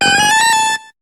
Cri de Porygon2 dans Pokémon HOME.